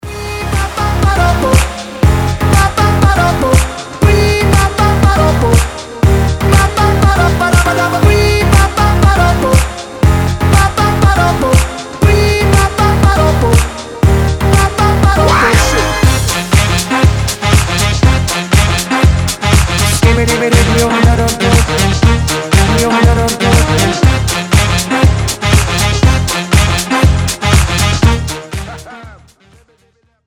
• Качество: 320, Stereo
зажигательные
веселые
Dance Pop
Саксофон
Euro House
Mashup